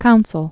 Coun·cil Bluffs
(kounsəl)